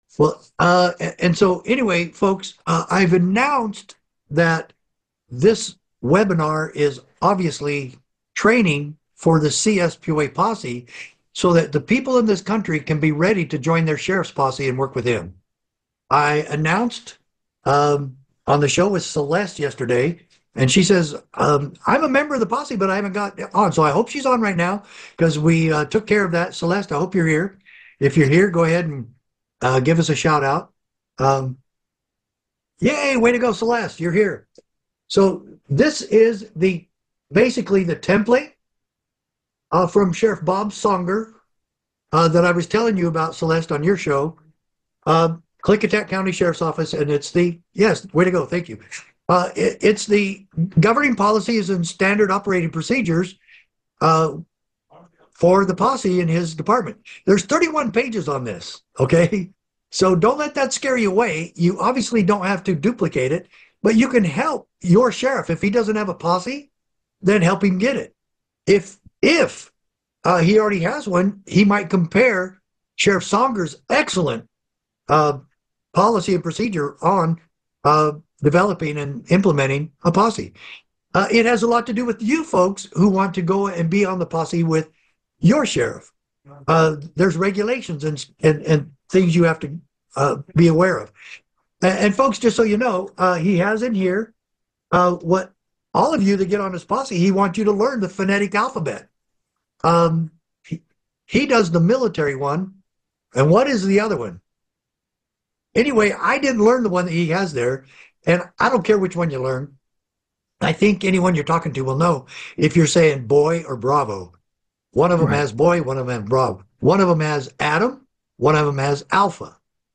➡ This webinar is a training for the CSPOA posse, a group that works with sheriffs across the country. The speaker discusses the importance of understanding the rules and procedures of the posse, including learning the phonetic alphabet for communication. The speaker also emphasizes the need for citizens to engage with their local sheriffs and support efforts to address national security issues, including illegal immigration.